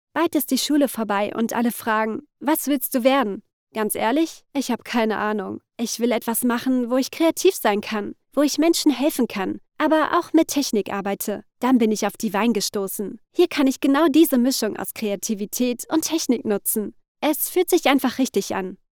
young german voice Female Voice Over Talent
I am from Germany and have a young and fresh Voice. I speak over 10 years for many big Companys and Dubbing Studios and have a professionall Equipment.
1216Sample_Natural.mp3